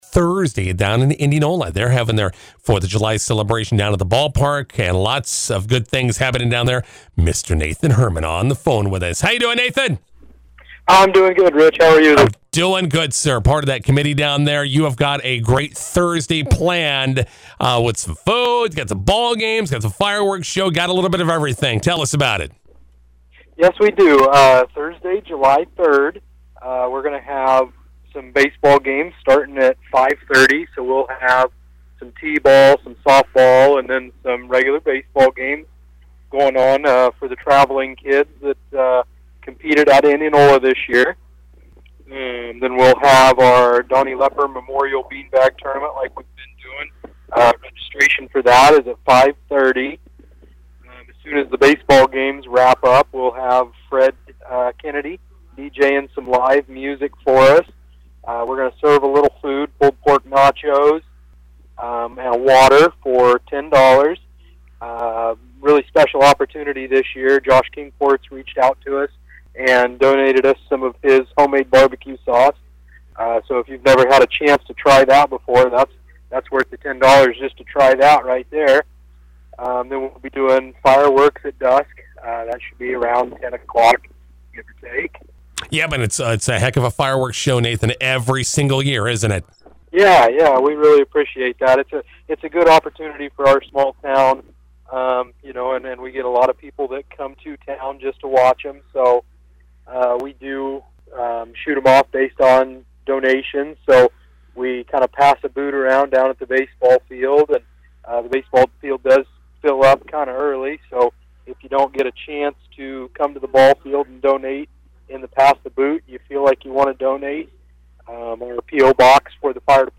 INTERVIEW: Indianola celebrating July 4th with food/ballgames/fireworks on Thursday.